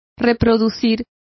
Complete with pronunciation of the translation of reproducing.